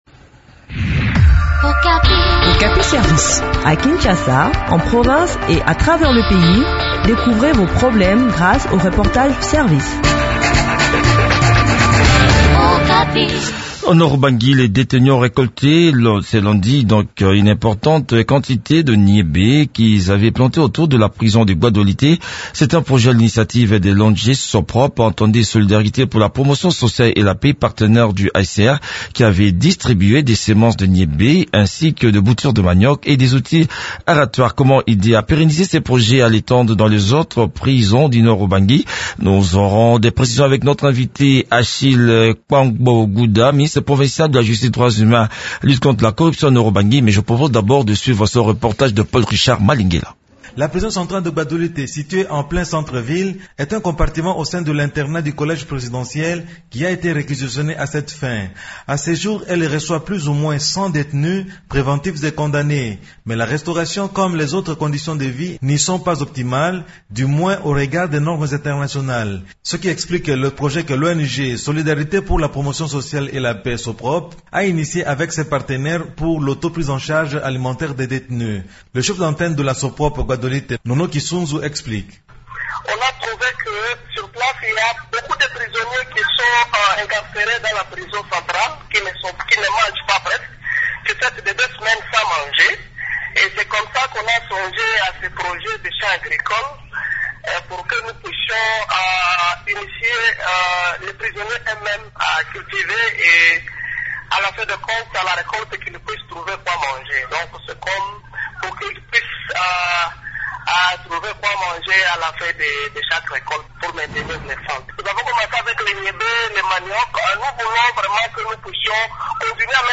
Le point sur le déroulement de ce projet dans cet entretien